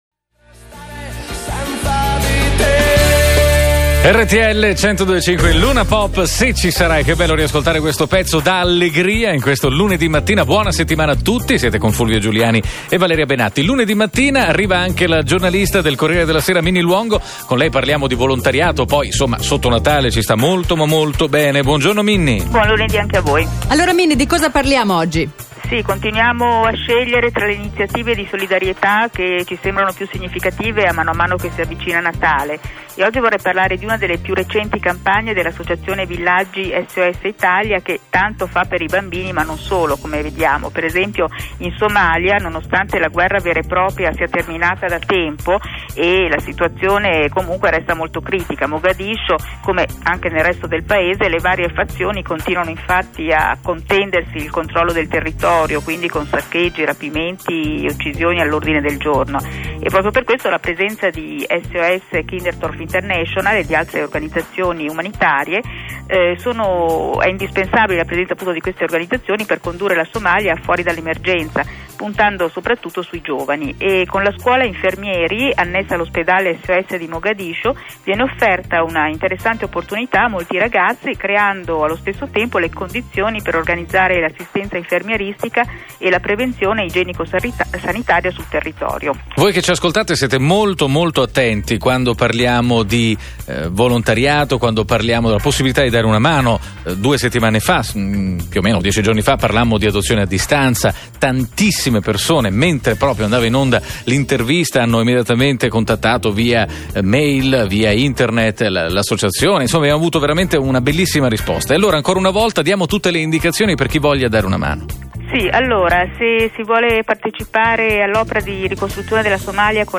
Radio RTL intervista a SOS-K in Somalia-Natale